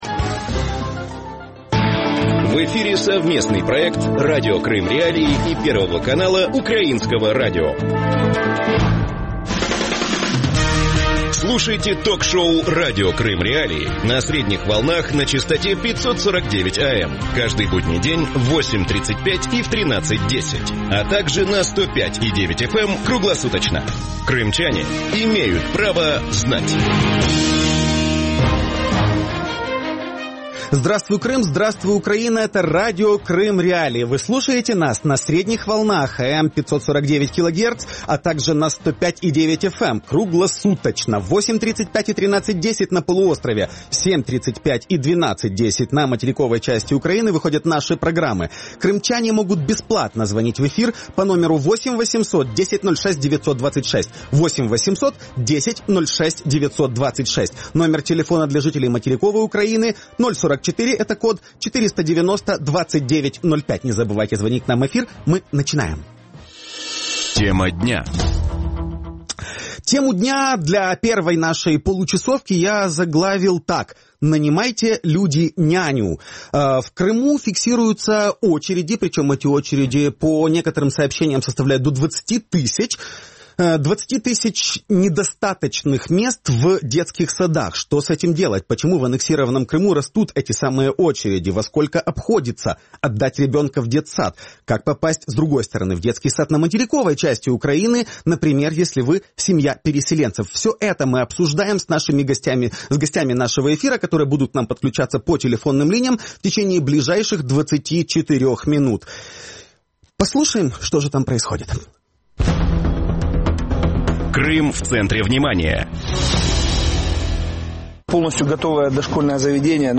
В Крыму слушайте 105.9 ФМ.